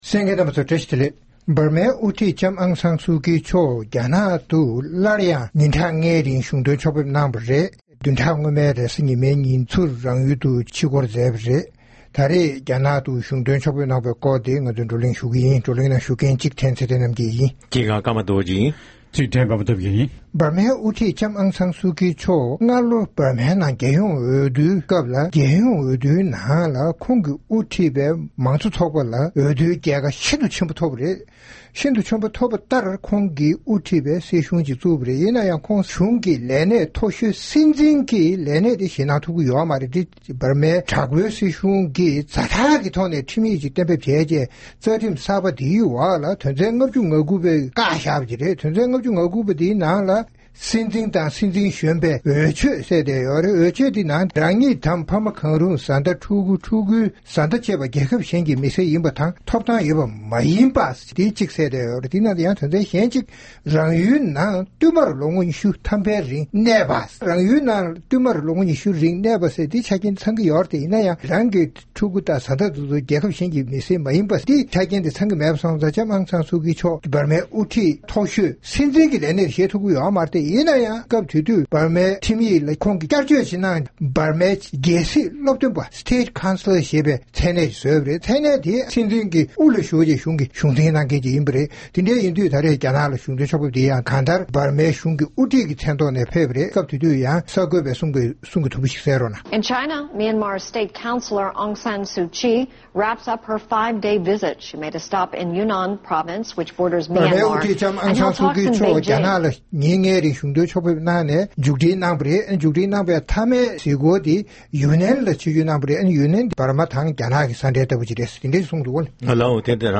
༄༅། །རྩོམ་སྒྲིག་པའི་གླེང་སྟེགས་ཞེས་པའི་ལེ་ཚན་ནང་། Myanmar འམ་འབར་མའི་དབུ་ཁྲིད་ལྕམ་ཨང་སན་སུ་ཅི་Aung San Suu Kyi མཆོག་བདུན་ཕྲག་སྔོན་མའི་ནང་ཉིན་གྲངས་ལྔའི་རིང་རྒྱ་ནག་ཏུ་གཞུང་དོན་ཕྱོགས་ཕེབས་གནང་བའི་སྐོར་རྩོམ་སྒྲིག་པ་རྣམས་ཀྱིས་བགྲོ་གླེང་གནང་བ་ཞིག་གསན་རོགས་གནང་།